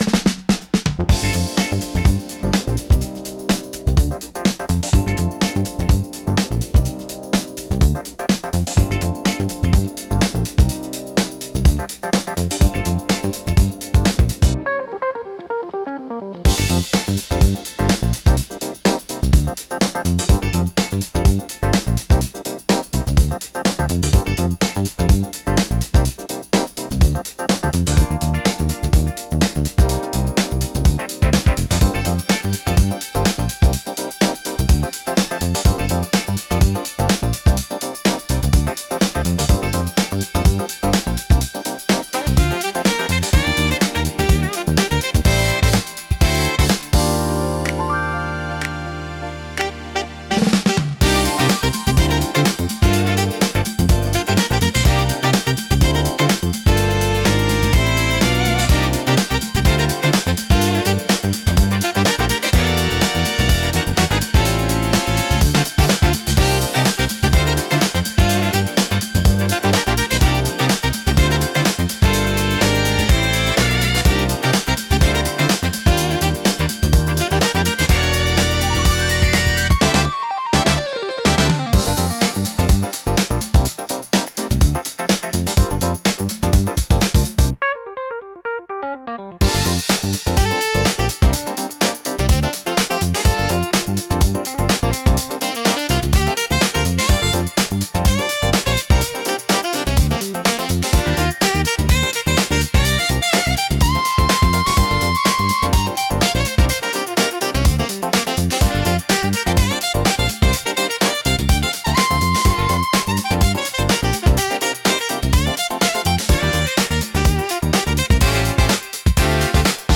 落ち着きつつも躍動感があり、聴く人の気分を盛り上げつつリラックスさせる効果があります。